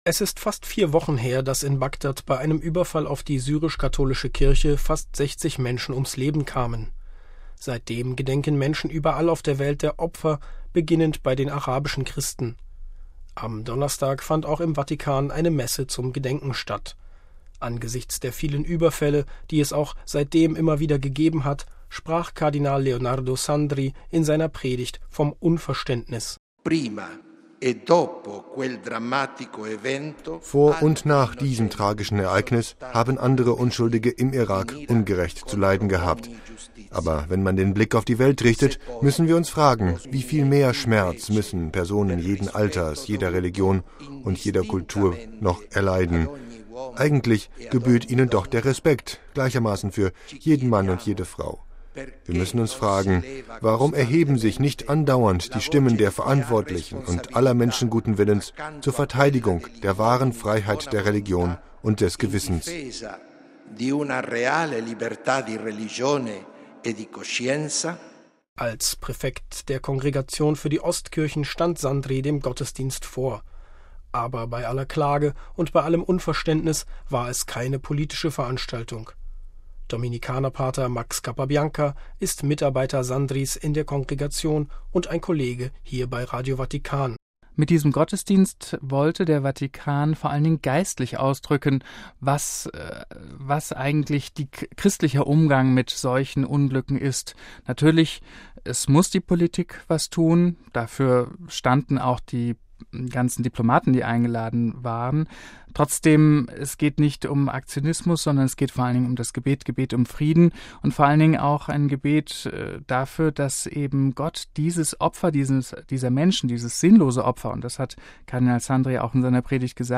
Am Donnerstag fand auch im Vatikan eine Messe zum Gedenken statt.